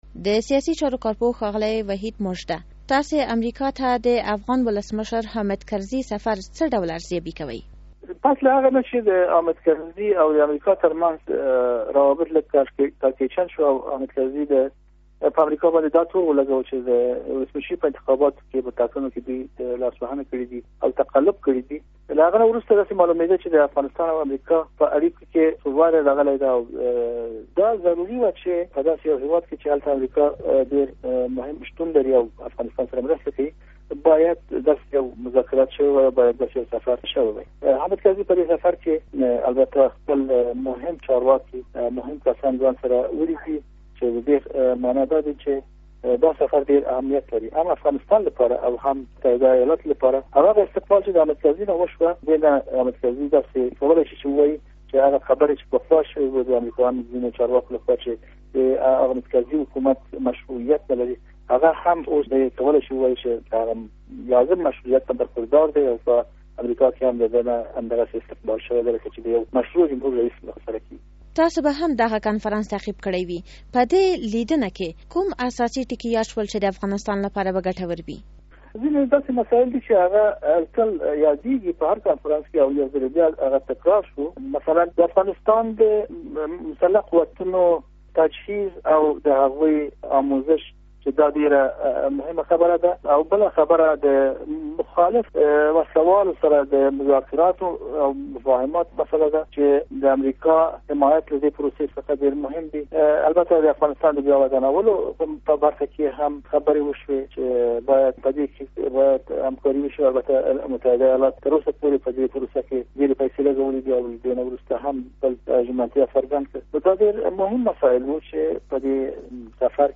مركه